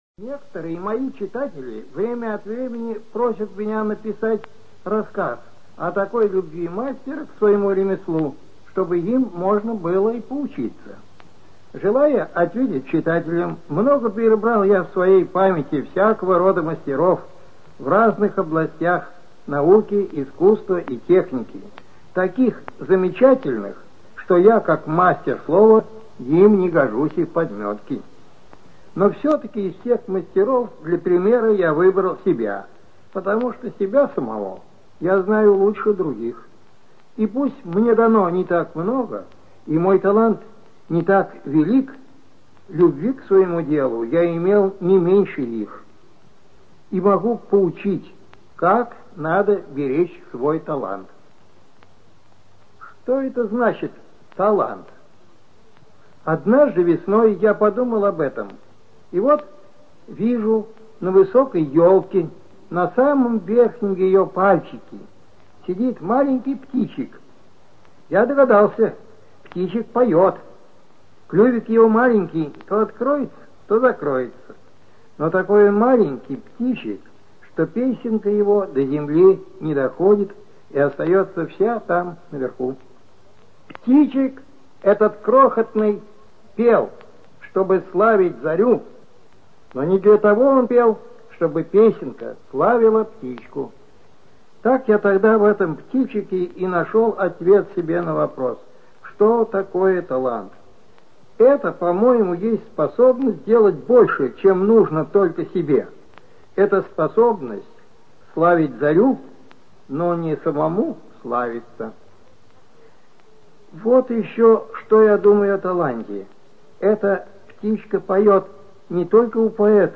ЧитаетАвтор
Пришвин М - Мои тетрадки (Автор)(preview).mp3